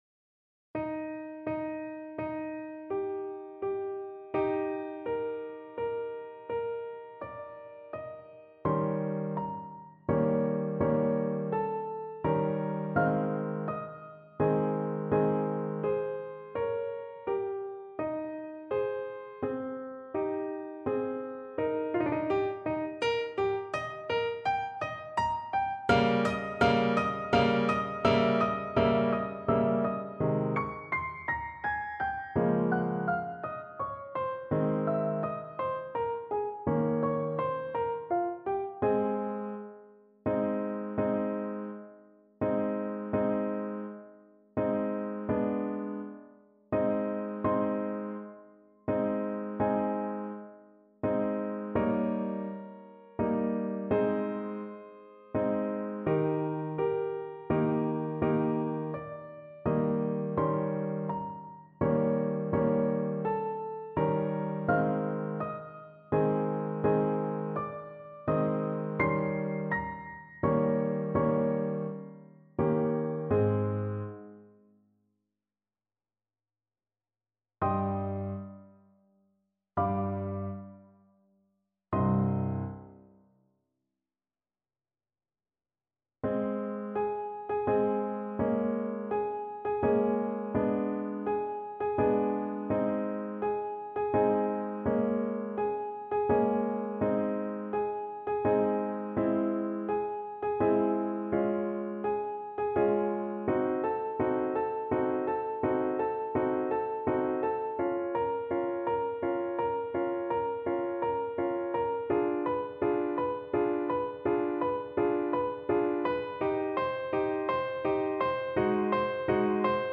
Free Sheet music for Trumpet
Ab major (Sounding Pitch) Bb major (Trumpet in Bb) (View more Ab major Music for Trumpet )
6/8 (View more 6/8 Music)
Andantino (=116) (View more music marked Andantino)
Trumpet  (View more Advanced Trumpet Music)
Classical (View more Classical Trumpet Music)